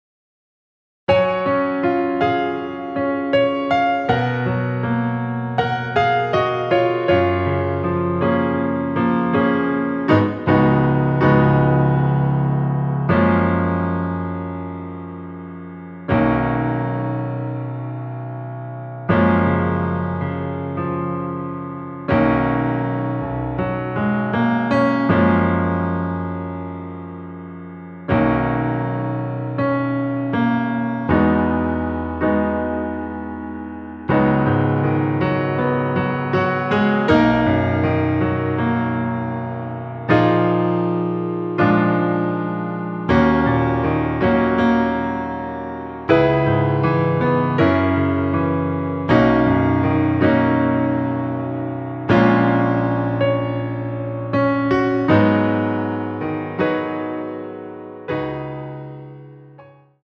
내린 MR 입니다.
F#
◈ 곡명 옆 (-1)은 반음 내림, (+1)은 반음 올림 입니다.
앞부분30초, 뒷부분30초씩 편집해서 올려 드리고 있습니다.
중간에 음이 끈어지고 다시 나오는 이유는